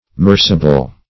merciable - definition of merciable - synonyms, pronunciation, spelling from Free Dictionary Search Result for " merciable" : The Collaborative International Dictionary of English v.0.48: Merciable \Mer"ci*a*ble\, a. [OF.]